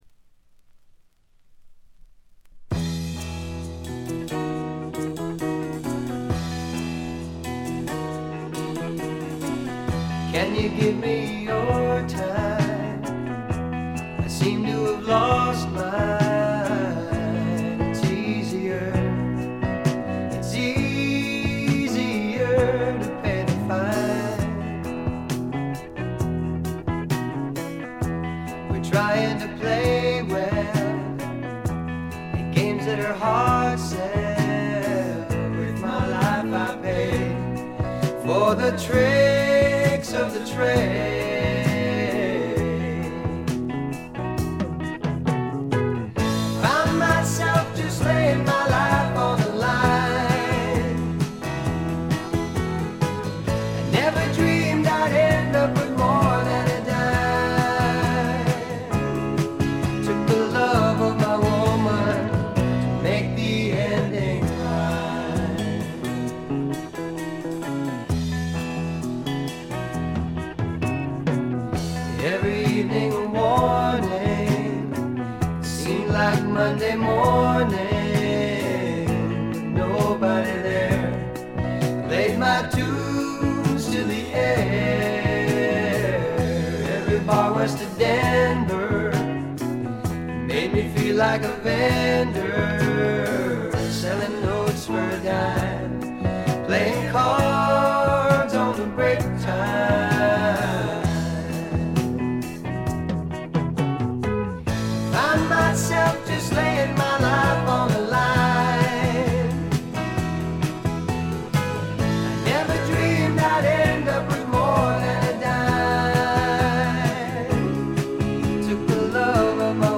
ほとんどノイズ感無し。
美しいコーラスが特に気持ち良いです。
試聴曲は現品からの取り込み音源です。
Recorded At - Kaye-Smith Studios